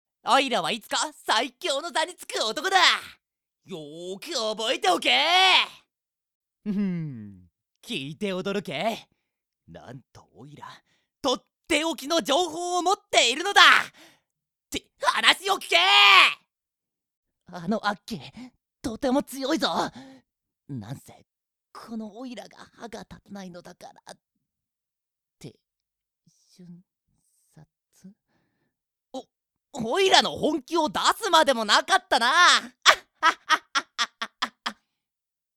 演じていただきました！